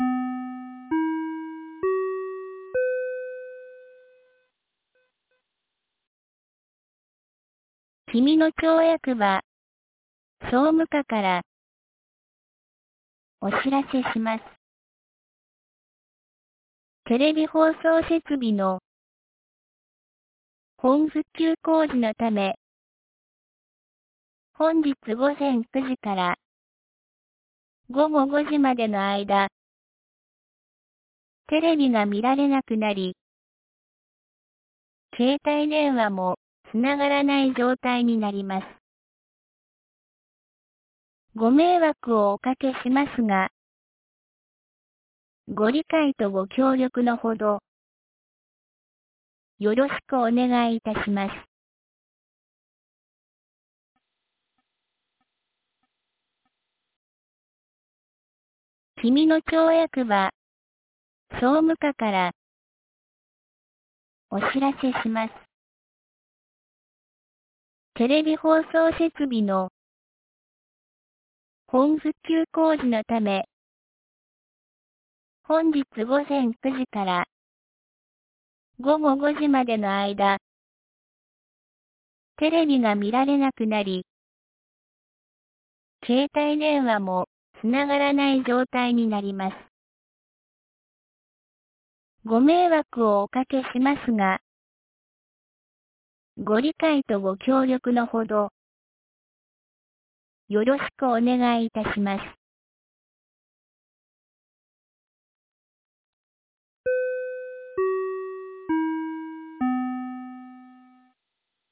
2024年10月29日 08時31分に、紀美野町より国吉地区へ放送がありました。